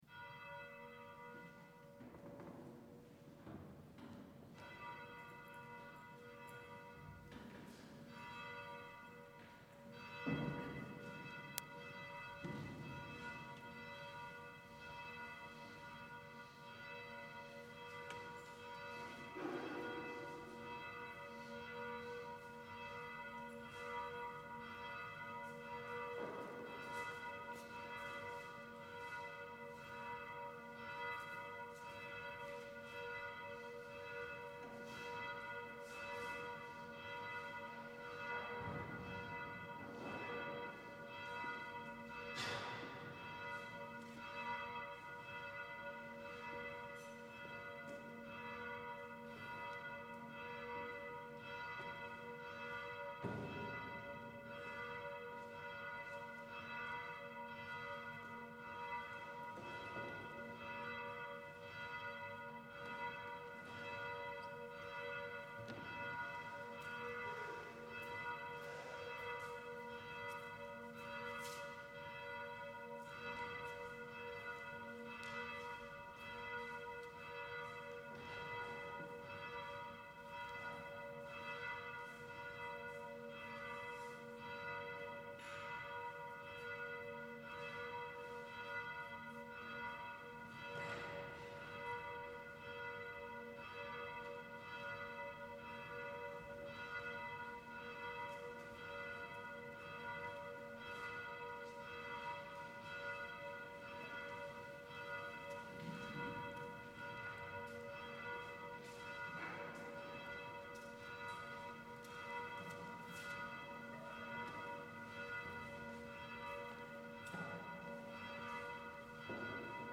Gregorian
Chanting Monk